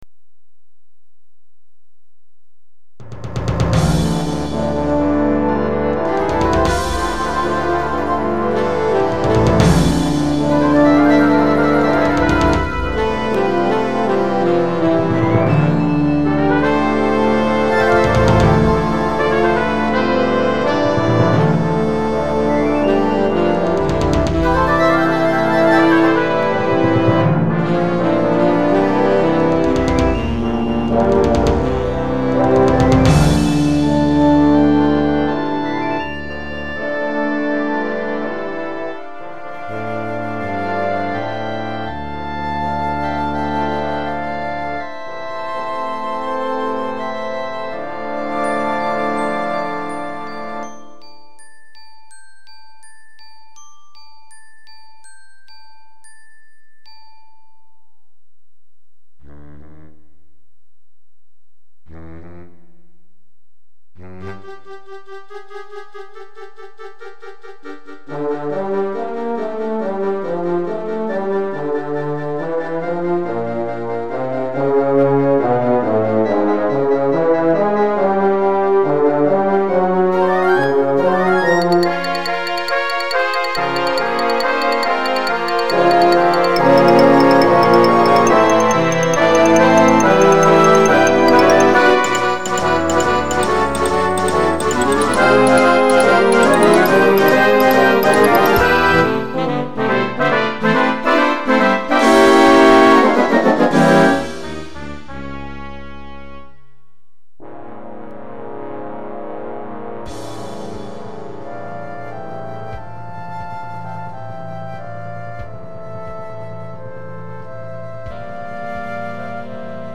吹奏楽のための序曲《龍神伝説》
Finaleの譜面を鳴らしただけのもので、音源の都合で打楽器など本来の音が鳴っていない場合があります。）